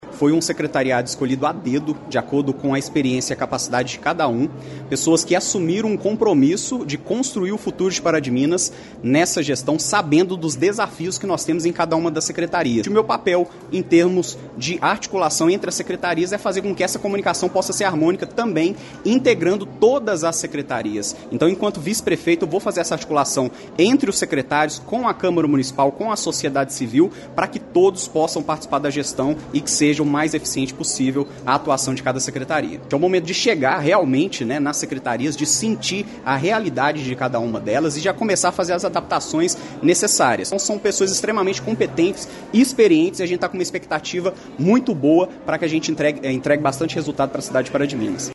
O vice-prefeito, Luiz Lima, destacou a capacidade técnica como o principal motivador para as escolhas feitas e ressaltou que pretende trabalhar alinhado aos novos secretários para uma gestão eficiente: